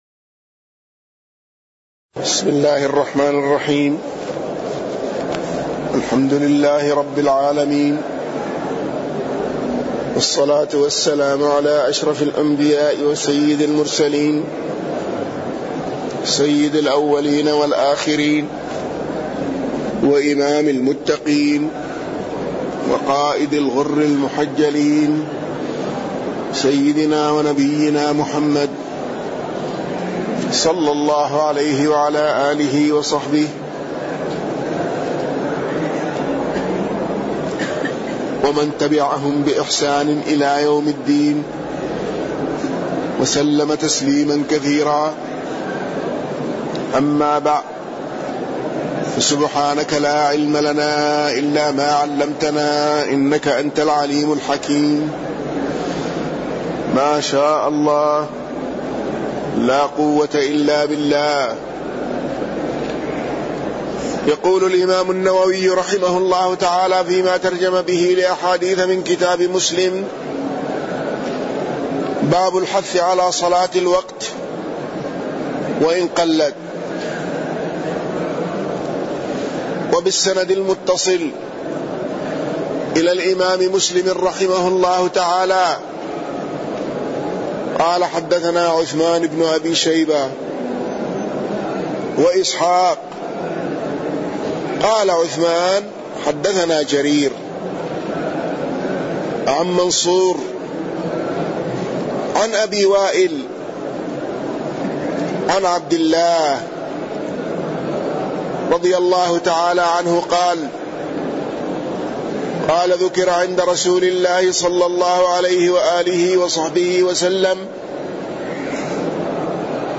تاريخ النشر ٣٠ صفر ١٤٣١ هـ المكان: المسجد النبوي الشيخ